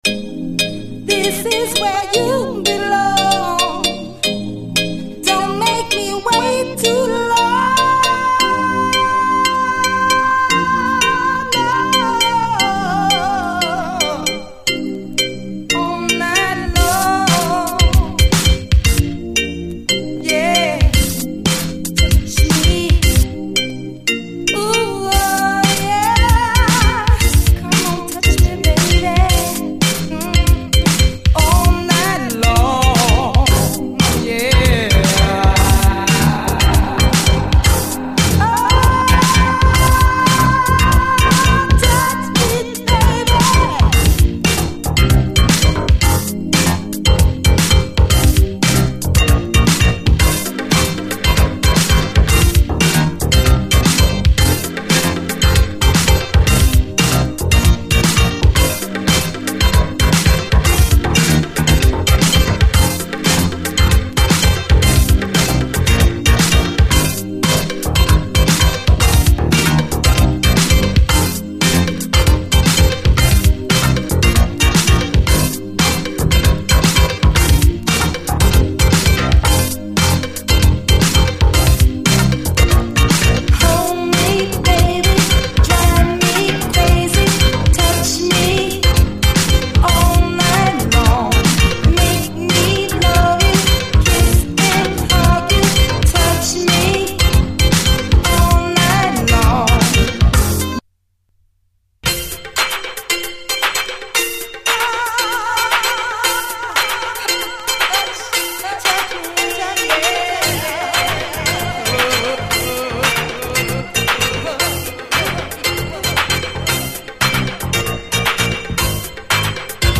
SOUL, 70's～ SOUL, DISCO
華やかなパーティー感満点！